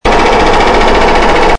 Demolition
Jackhammer 4 00:02
jackhammer-04.mp3